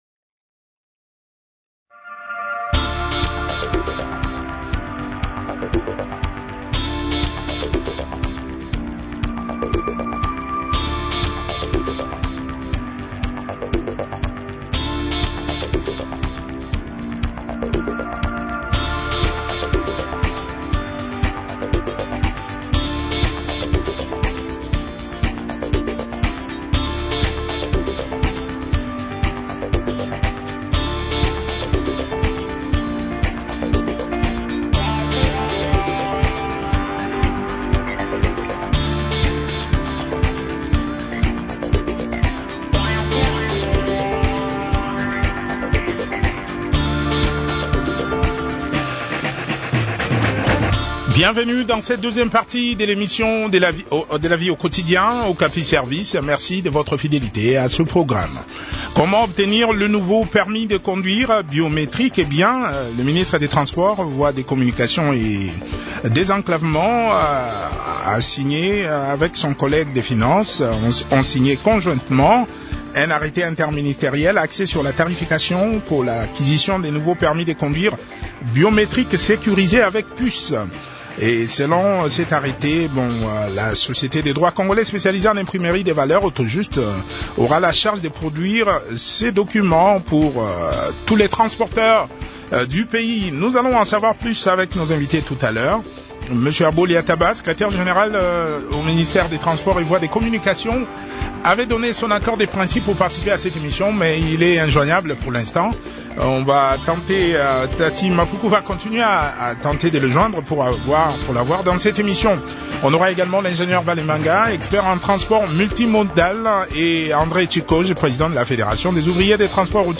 a aussi participé à cette interview.